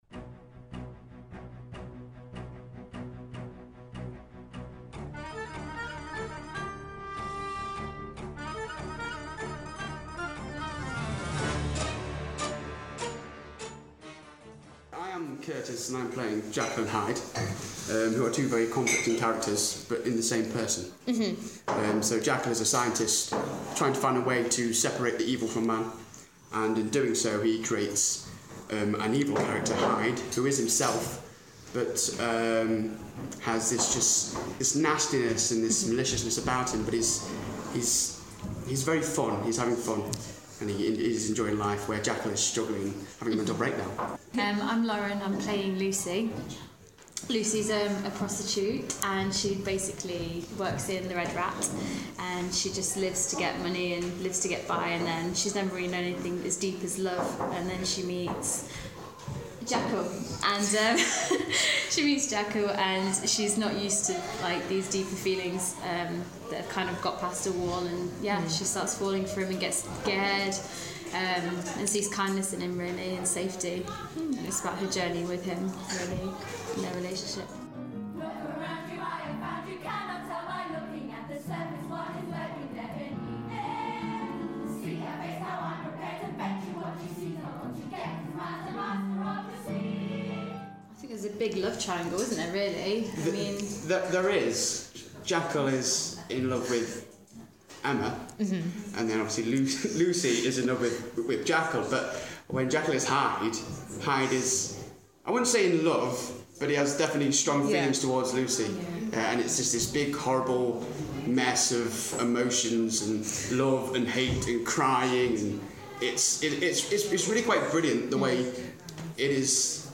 I crashed one of the rehearsals of The People's Theatre Company's production of Jekyll & Hyde, on at the Arts Theatre from 23rd-26th March.